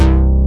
BAS_MaxiSynBas.wav